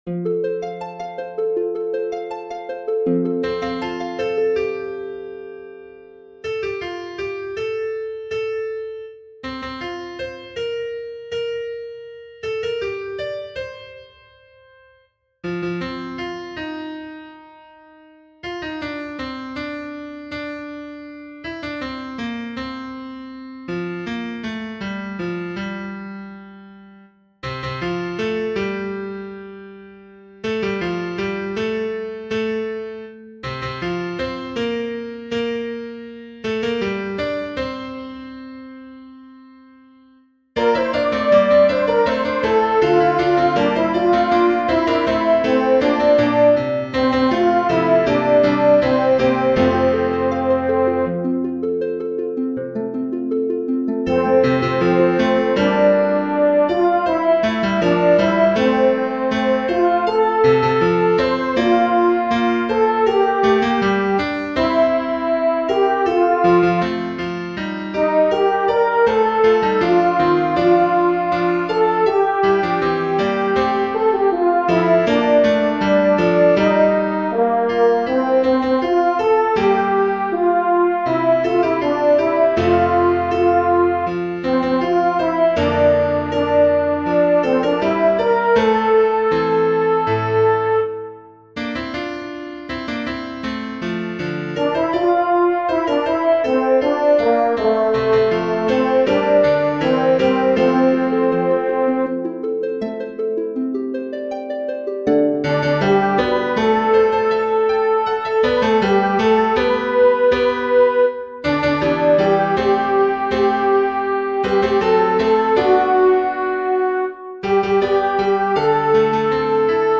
Sounds a little funny at first, but the score is very helpful.
angels_carol-alto.mp3